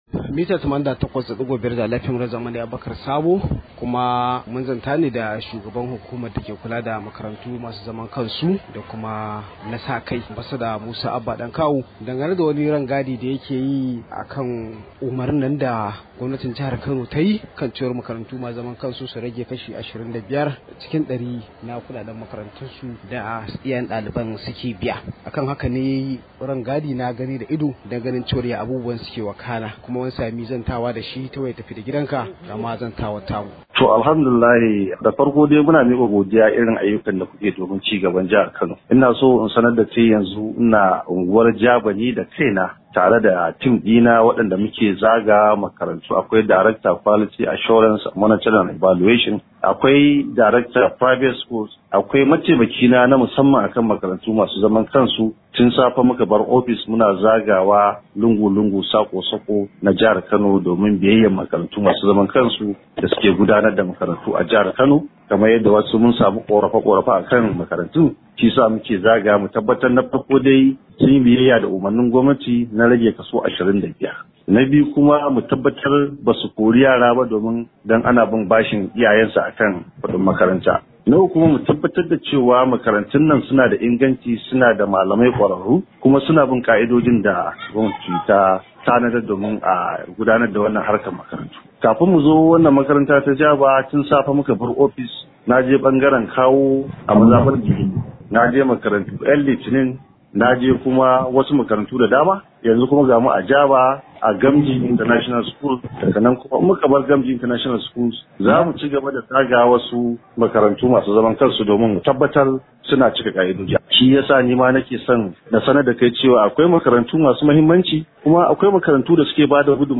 Rahoto: Gwamnatin Kano ta zagaya makarantu masu zaman kansu a kan rage kaso 25